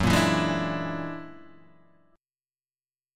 F# Augmented 9th